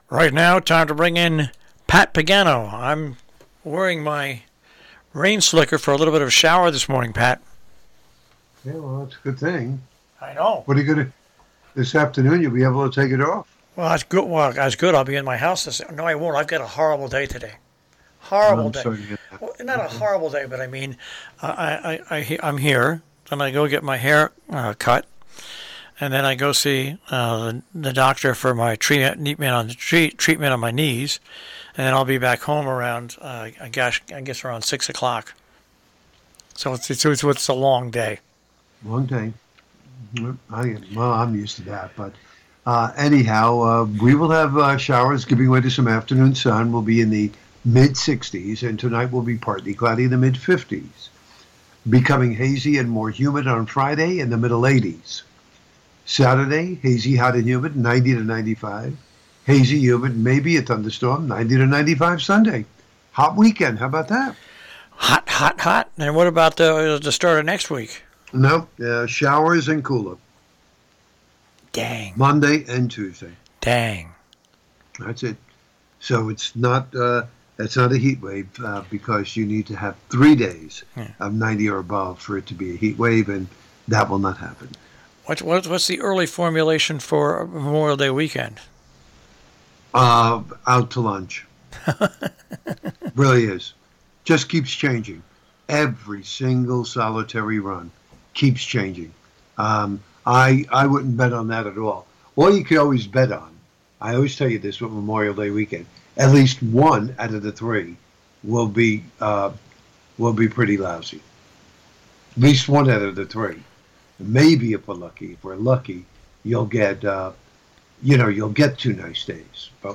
Your Robin Hood Radio Tri-State Forecast